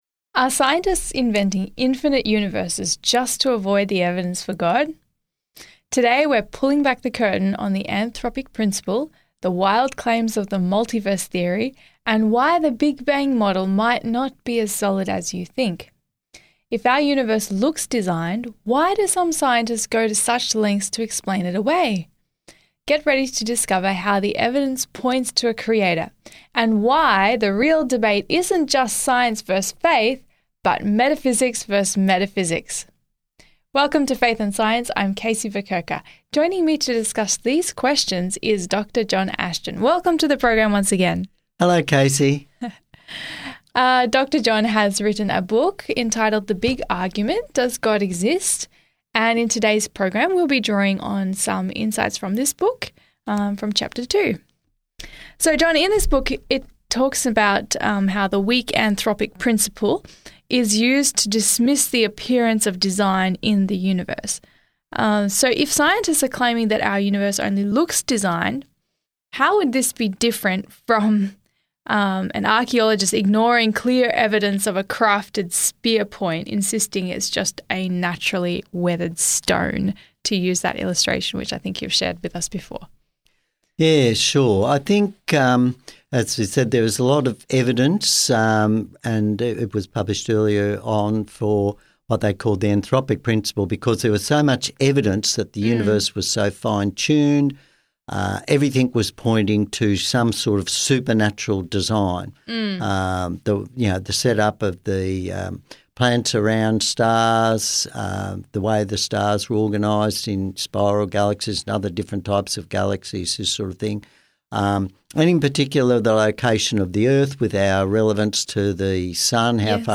Dive into a thought‑provoking conversation exploring the anthropic principle, the multiverse theory, and the fine‑tuning of the universe as evidence for a Creator.